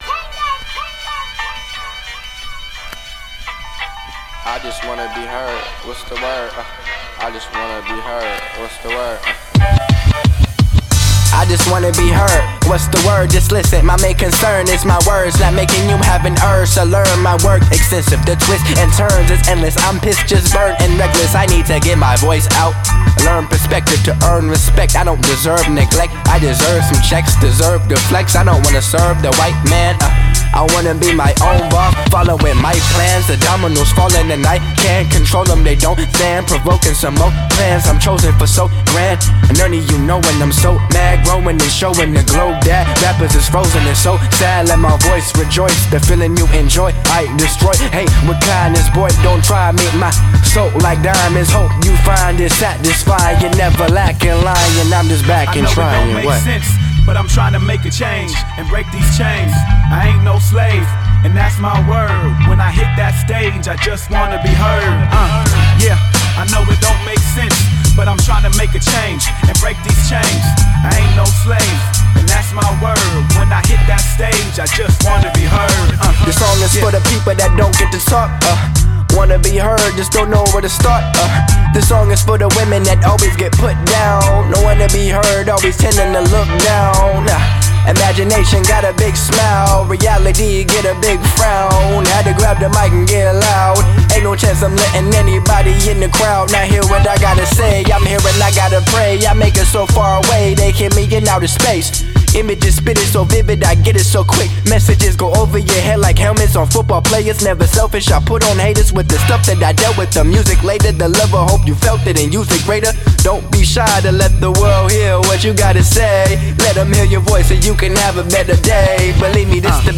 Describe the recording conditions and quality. Recorded at AD1 Studios, Seattle, WA